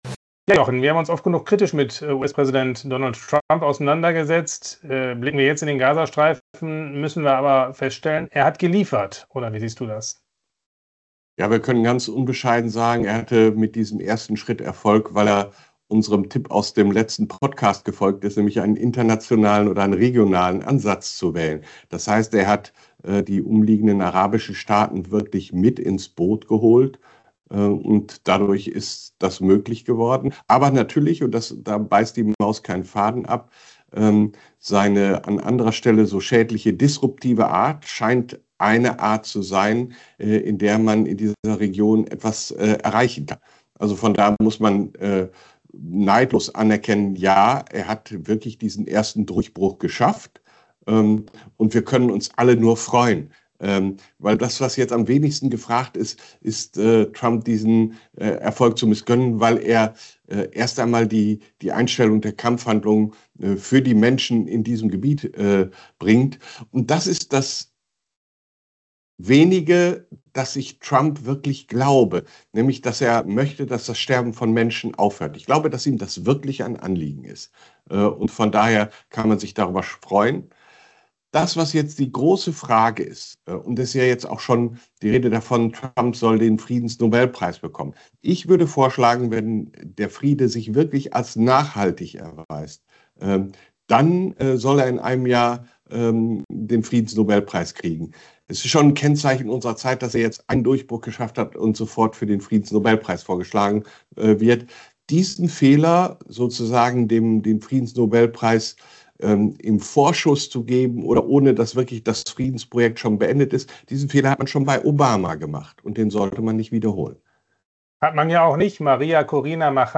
Special Guest: Michael Wolffsohn